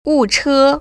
[wù//chē]